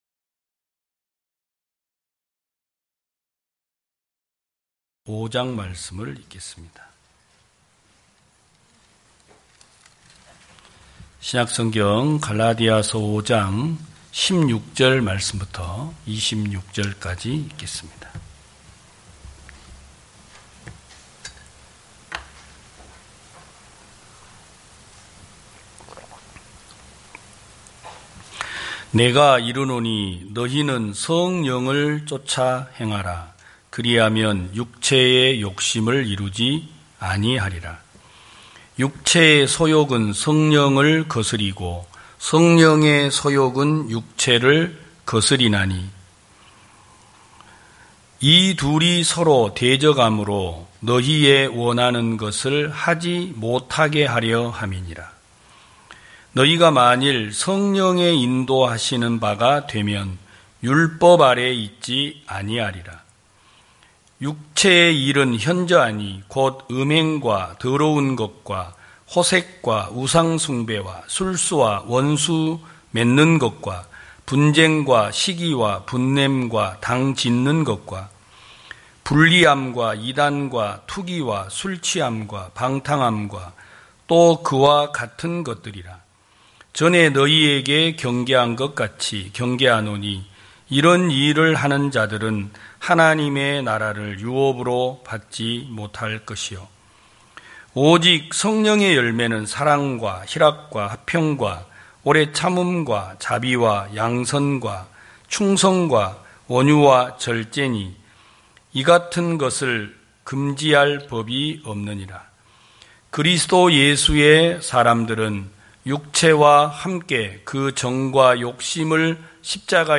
2021년 08월 22일 기쁜소식부산대연교회 주일오전예배
성도들이 모두 교회에 모여 말씀을 듣는 주일 예배의 설교는, 한 주간 우리 마음을 채웠던 생각을 내려두고 하나님의 말씀으로 가득 채우는 시간입니다.